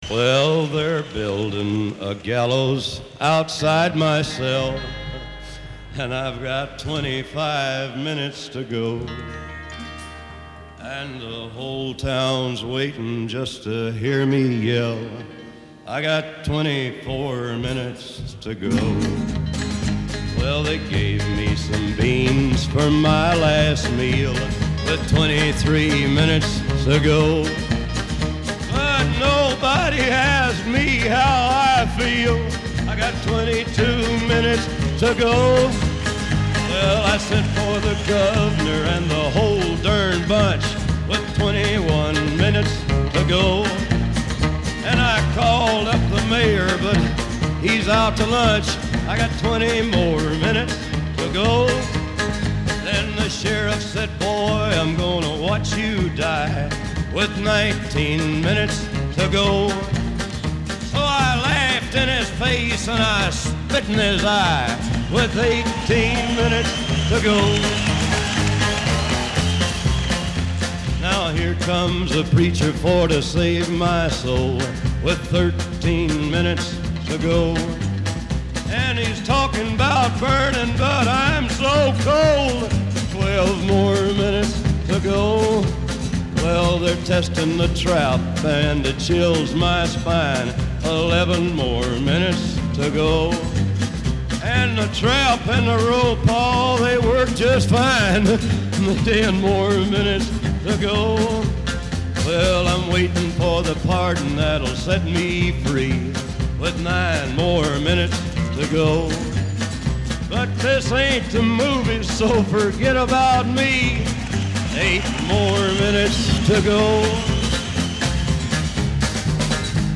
live @ Folsom Prison 1968